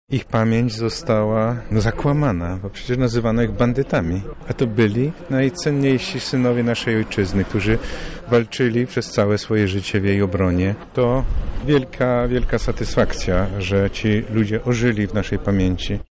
Poświęcenie pomnika było jednym z punktów obchodów Narodowego Dnia Pamięci Żołnierzy Wyklętych.
mówi abp Stanisław Budzik, Metropolita Lubelski.